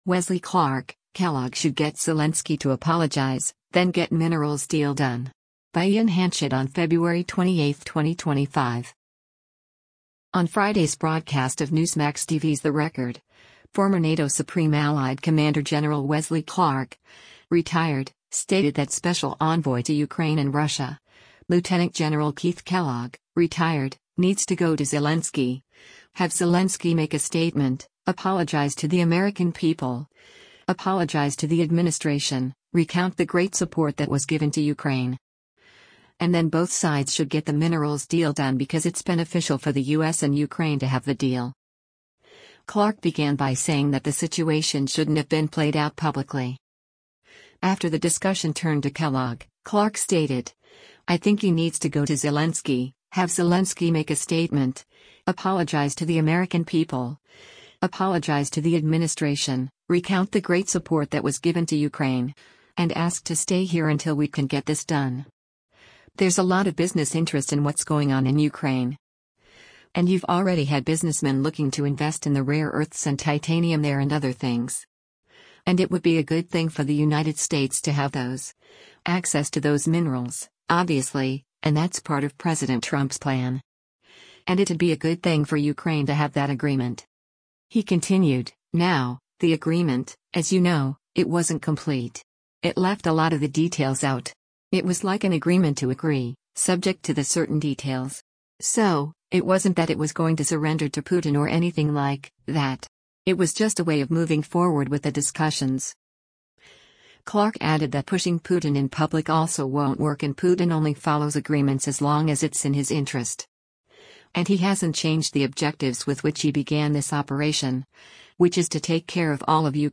On Friday’s broadcast of Newsmax TV’s “The Record,” former NATO Supreme Allied Commander Gen. Wesley Clark (Ret.) stated that Special Envoy to Ukraine and Russia, Lt. Gen. Keith Kellogg (Ret.) “needs to go to Zelensky, have Zelensky make a statement, apologize to the American people, apologize to the administration, recount the great support that was given to Ukraine.”